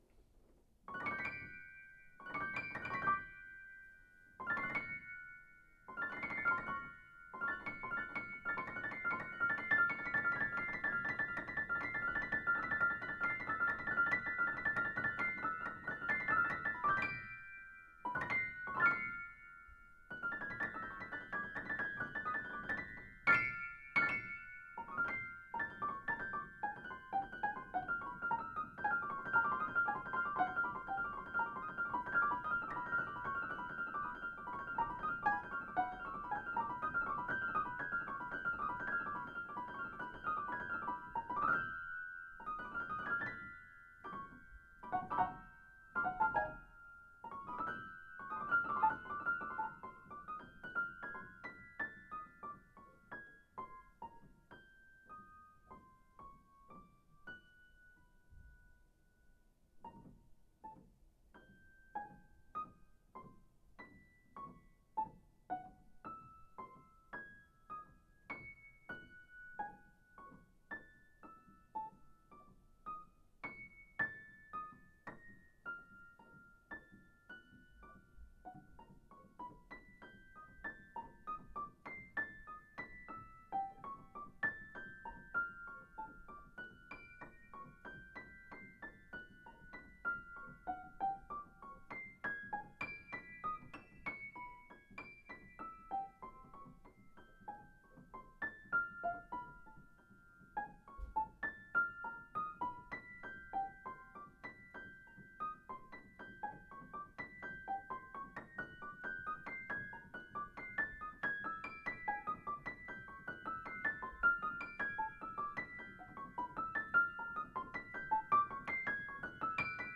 improv 1-28-13: freezing rain